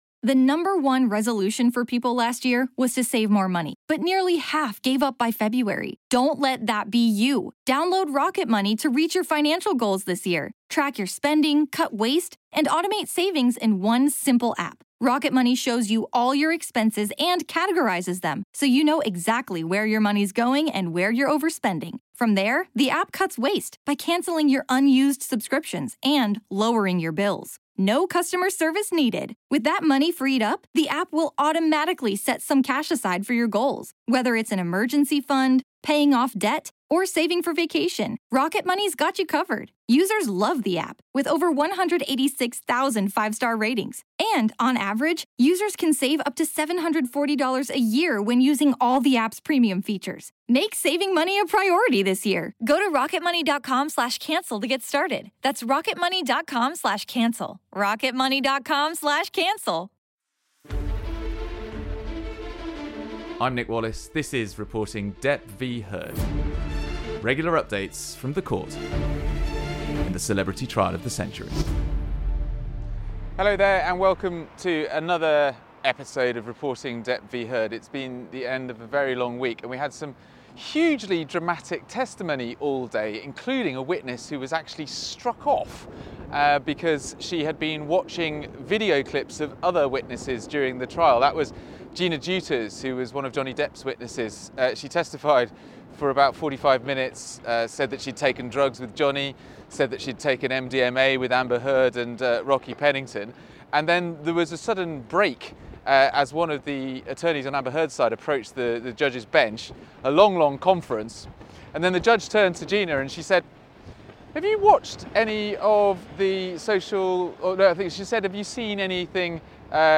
park bench interview